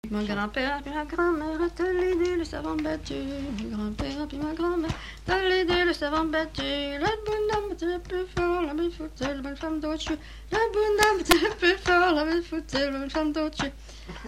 Divertissements d'adultes - Couplets à danser
danse : branle : courante, maraîchine
Pièce musicale inédite